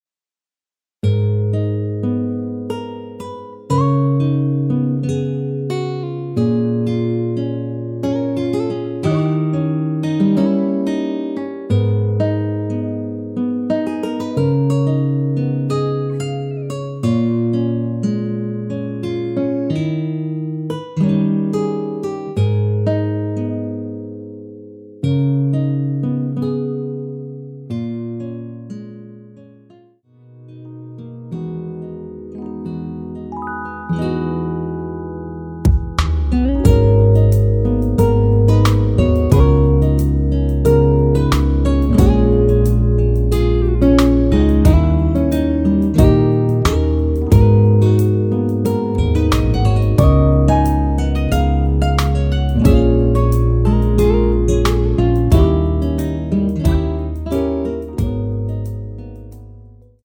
Abm
◈ 곡명 옆 (-1)은 반음 내림, (+1)은 반음 올림 입니다.
앞부분30초, 뒷부분30초씩 편집해서 올려 드리고 있습니다.
중간에 음이 끈어지고 다시 나오는 이유는